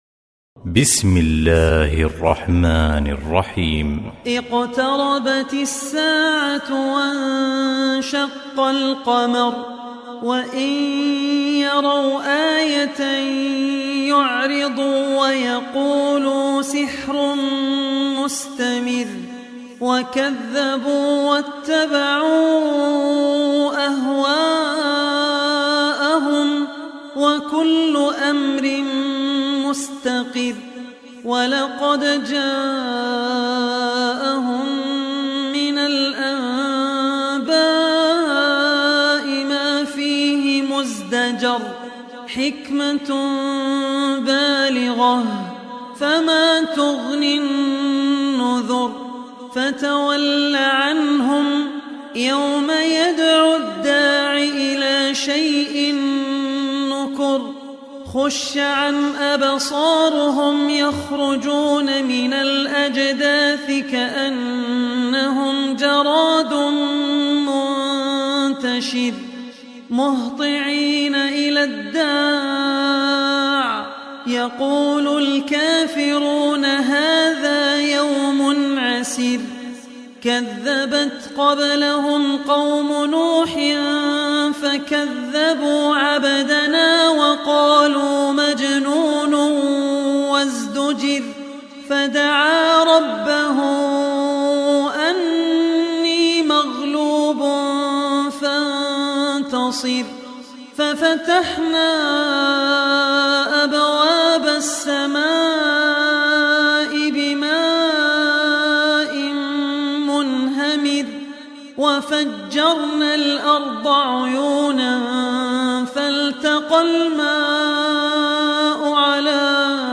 Surah Al Qamar Beautiful Recitation MP3 Download By Abdul Rahman Al Ossi in best audio quality.
surah al qamar surah al qamar mp3 surah al qamar tilawat surah al qamar audio surah al qamar tilawat download surah al qamar tilawat free surah al qamar audio download surah al qamar tilawat download abdul rahman surah al qamar